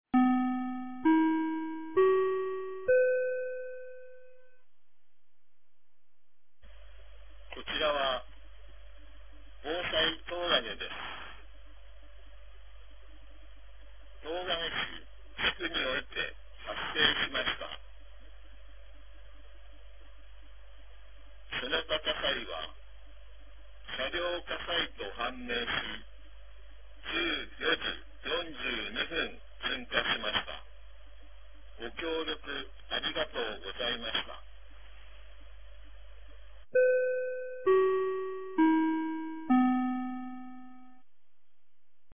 2025年01月22日 14時46分に、東金市より防災行政無線の放送を行いました。